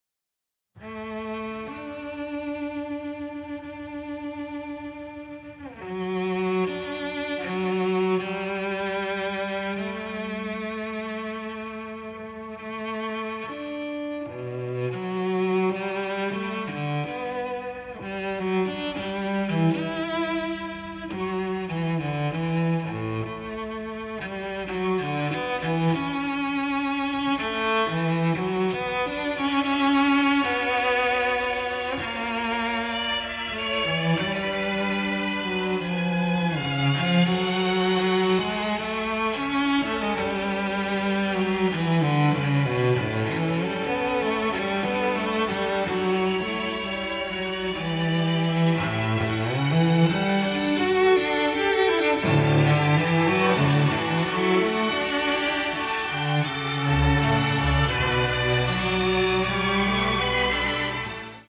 tender and powerful score
features several original emotional cues